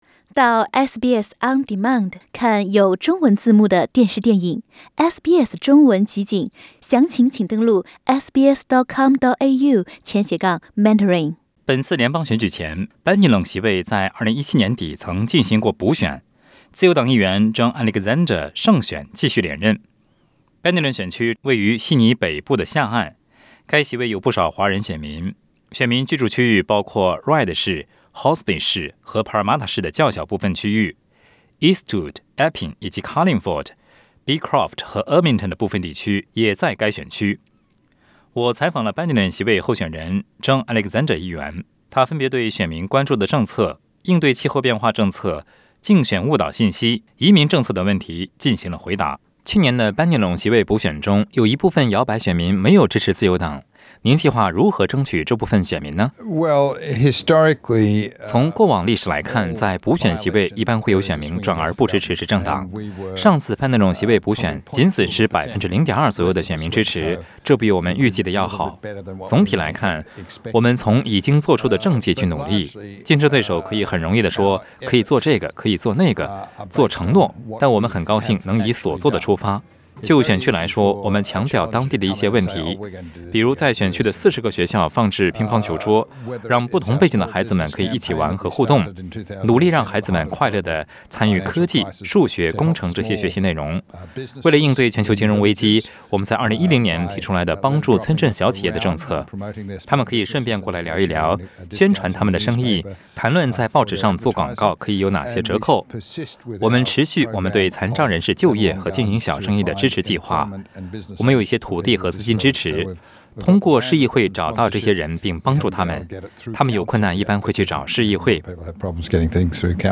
在接受本台采访时，Bennelong席位候选人John Alexander议员分别对选民关注政策，应对气候变化政策，竞选误导信息，移民政策等问题进行了回答。